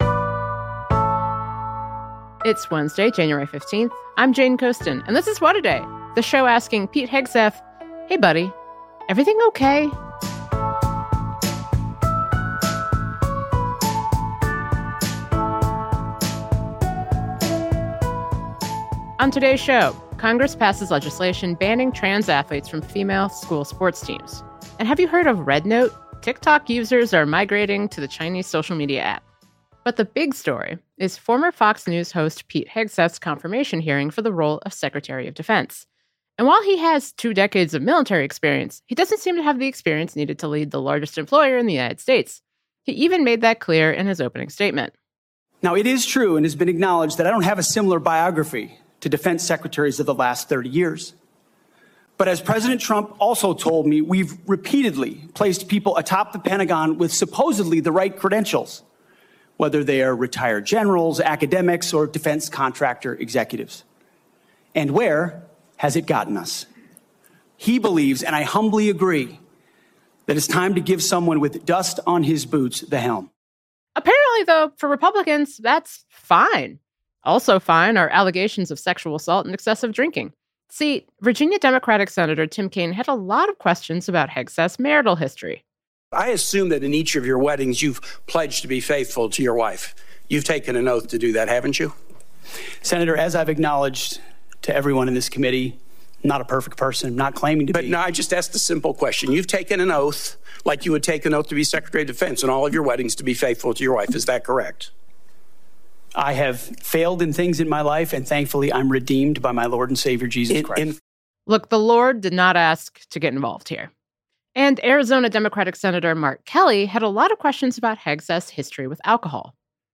Veteran and Illinois Democratic Sen. Tammy Duckworth joins us to share her thoughts on Tuesday’s hearing.